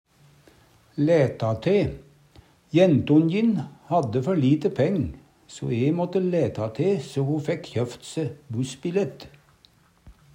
DIALEKTORD PÅ NORMERT NORSK læta te gje i tillegg Eksempel på bruk Jent'onjin hadde før lite peng, so e måtte læta te so ho fekk kjøft se bussbillett.